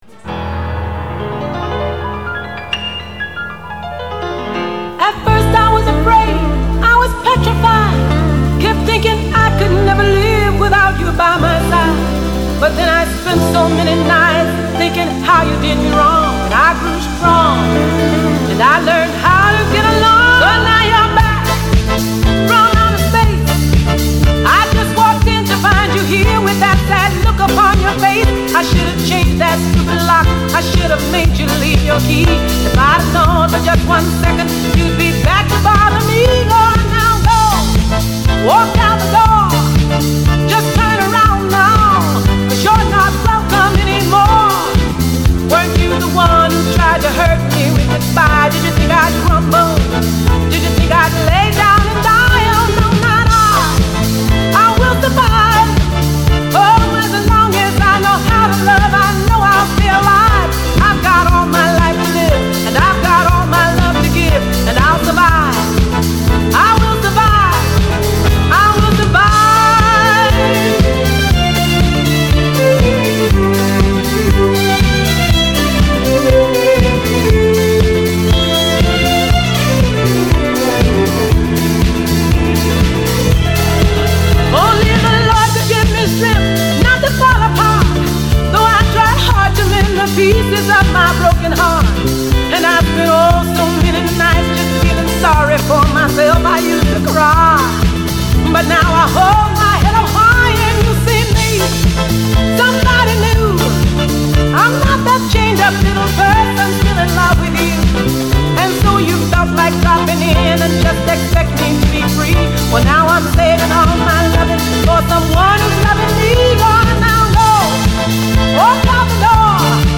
Música animada